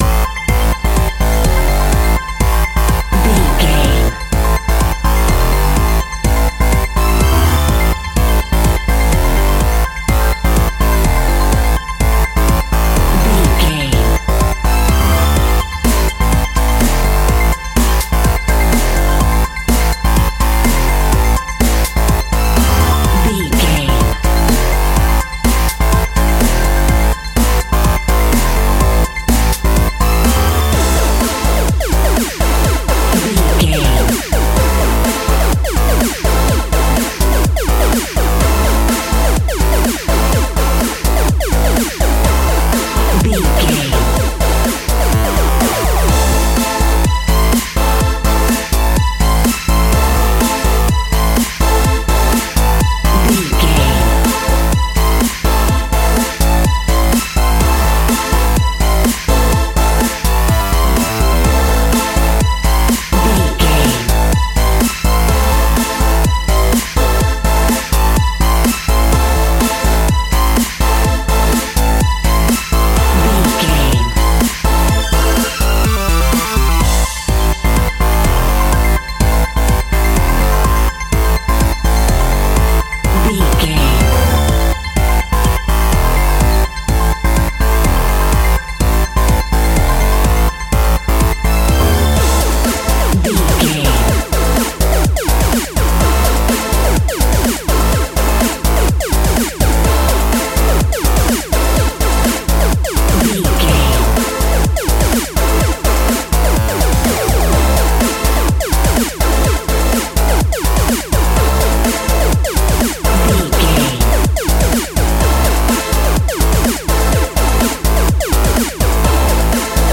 Electric Dirty House Music.
Aeolian/Minor
aggressive
dark
futuristic
industrial
frantic
synthesiser
drums
strings
electronic
techno
synth leads
synth bass